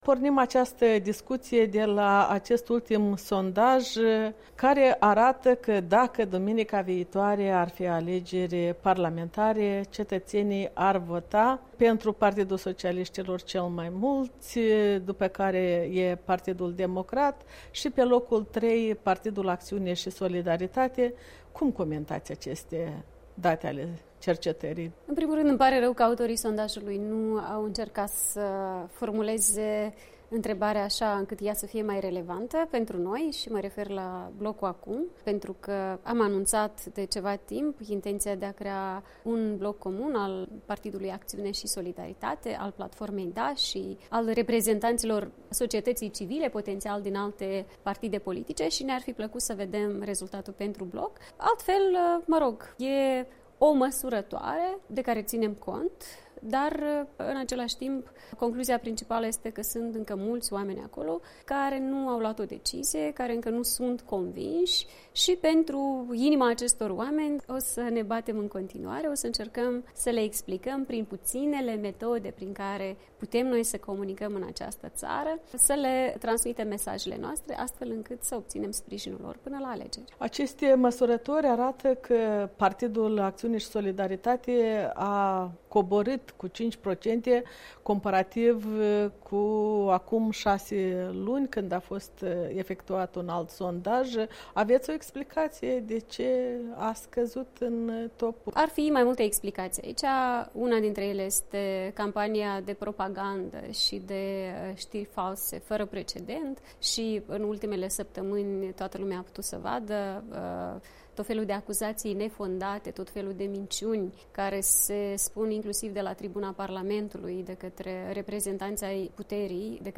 Maia Sandu în dialog cu Europa Liberă